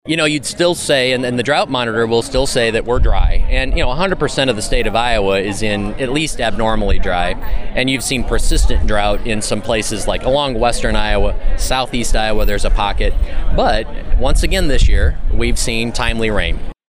Iowa Ag Secretary Mike Naig says a lot of farmers are thankful for the rains.